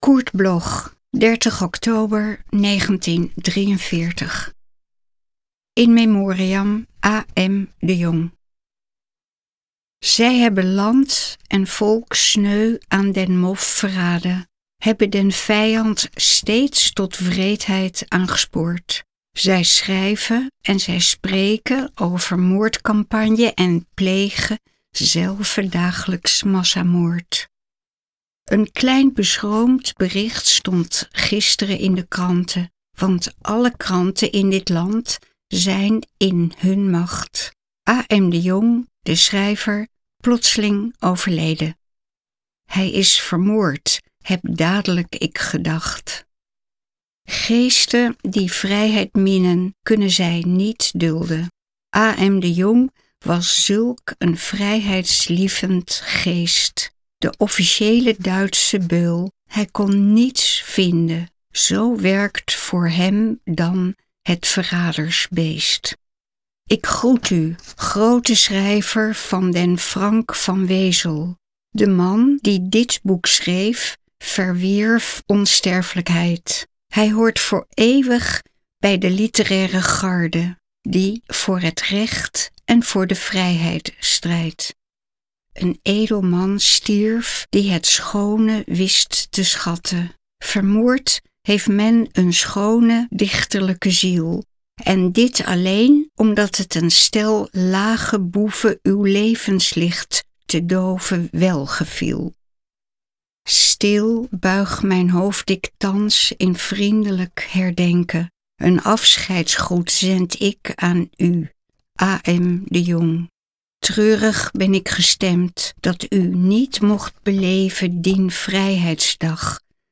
Recording: Studio Levalo, Amsterdam · Editing: Kristen & Schmidt, Wiesbaden